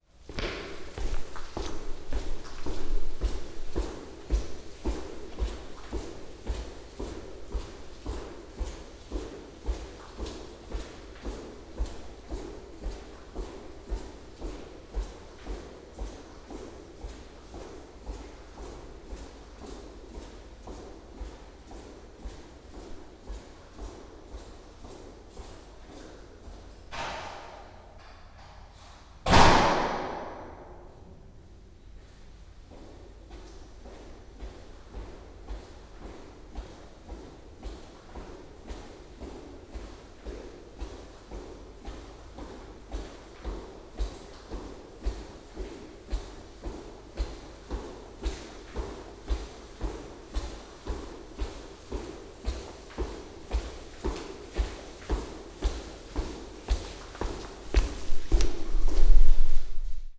Steps into a corridor
Concretamente 50 pasos, puerta, 50 pasos.
[ENG] Specifically 50 steps, door, 50 steps.
corridor-steps.wav